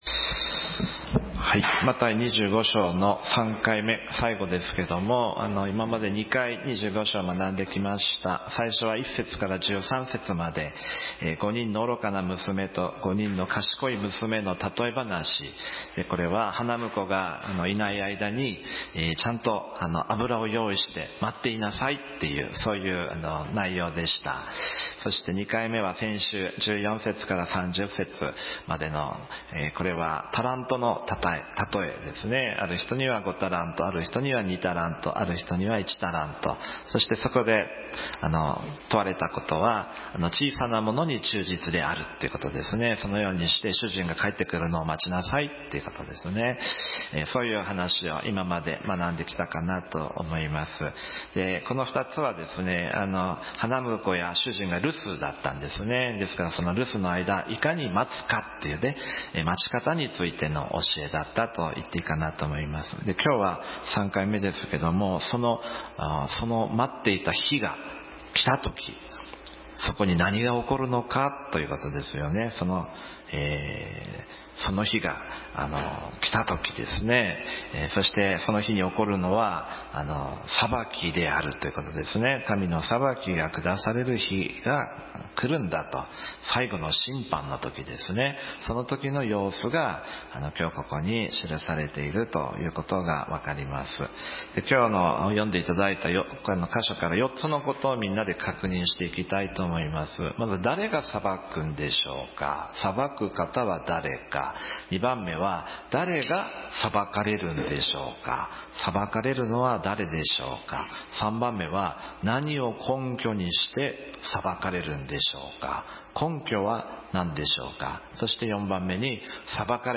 このメッセージは、マタイの福音書25章の最後の場面についての説教です。